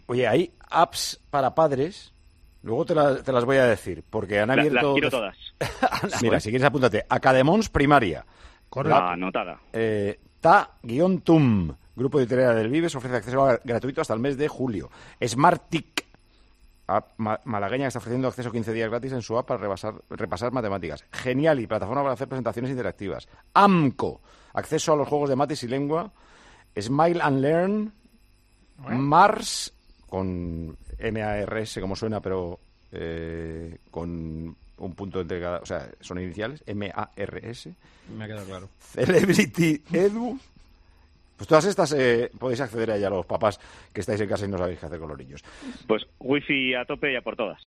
Paco González, director de Tiempo de Juego, nos dice una serie de aplicaciones para entretener y aprovechar el tiempo con los hijos